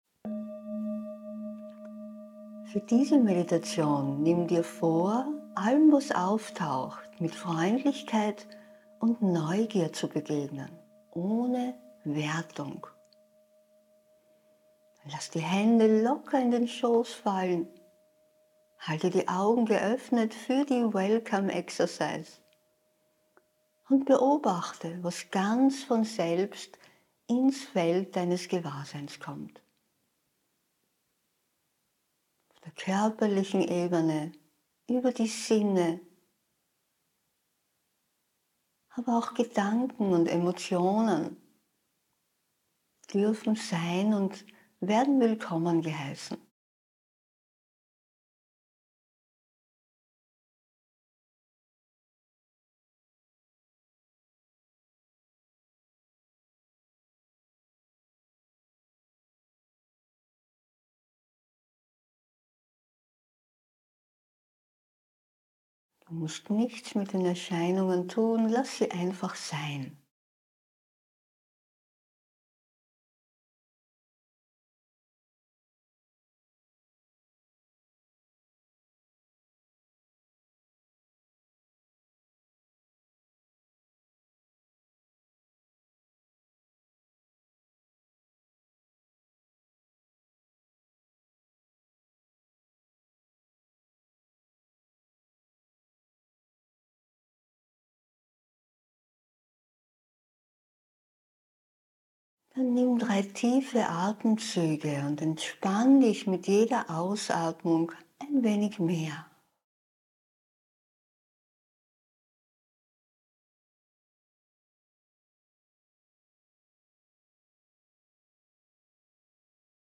9-Minuten-Meditation-Wahrnehmung.mp3